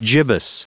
Prononciation du mot gibbous en anglais (fichier audio)
Prononciation du mot : gibbous